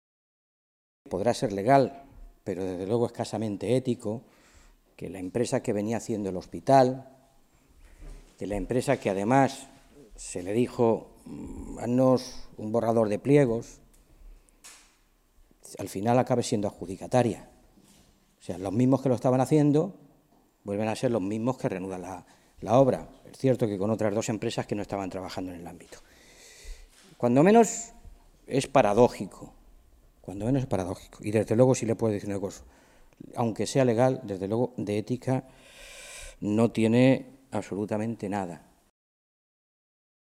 Mora se pronunciaba de esta manera esta mañana, en una comparecencia ante los medios de comunicación en Toledo en la que anunciaba que, una vez adjudicaba la nueva obra, los socialistas en el Parlamento autonómico “pediremos el expediente completo, porque hay cosas muy sorprendentes”, insistía.
Cortes de audio de la rueda de prensa